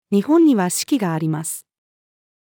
日本には四季があります。-female.mp3